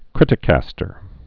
(krĭtĭ-kăstər)